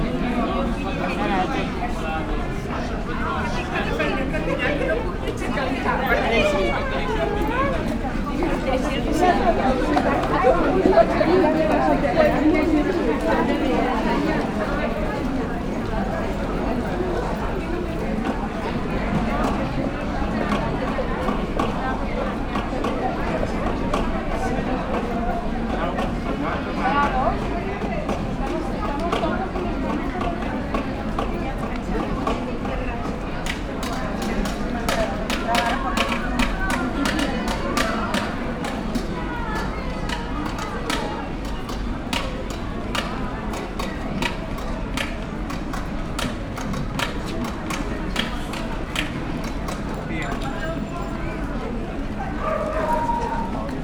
setalok_velence_omni_sds00.53.WAV